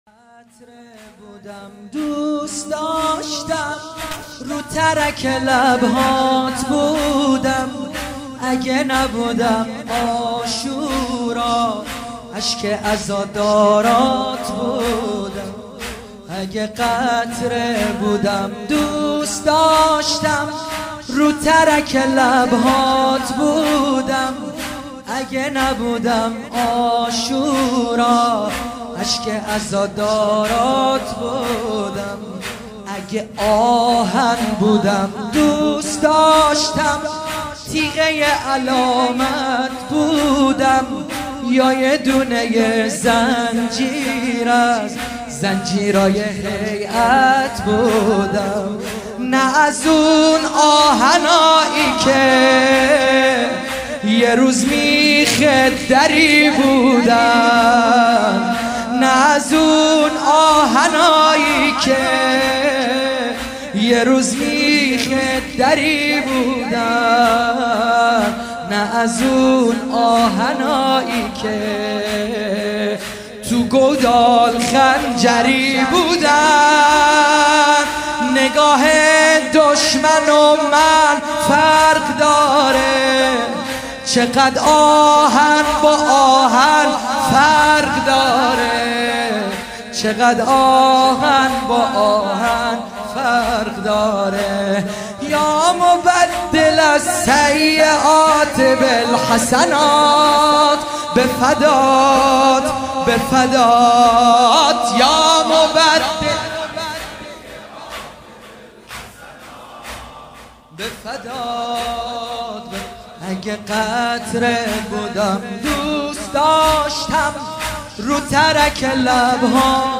زمینه | اگه قطره بودم دوست داشتم رو ترک لبهات بودم
مداحی
در شب ششم محرم 1441 هجری قمری